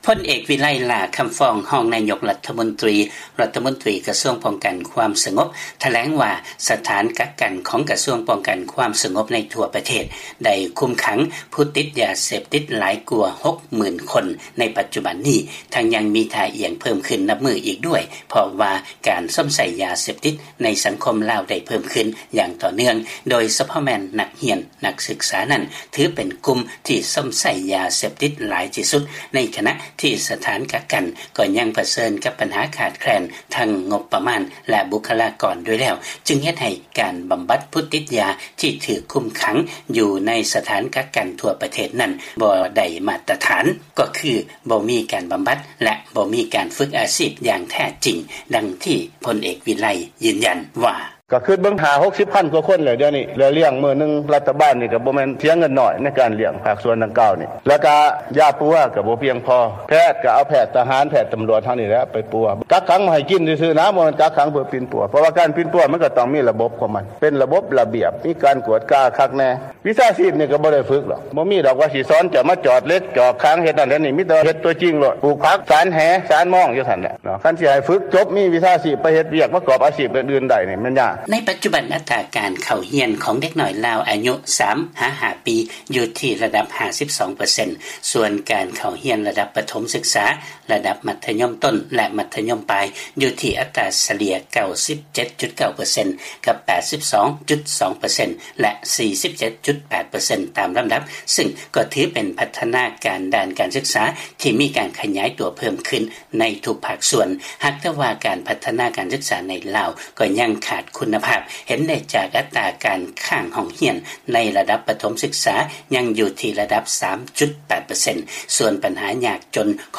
ເຊີນຟັງລາຍງານກ່ຽວກັບຮອງນາຍົກເວົ້າວ່າ ການບຳບັດຜູ້ຕິດຢາເສບຕິດໃນລາວບໍ່ໄດ້ມາດຕະຖານ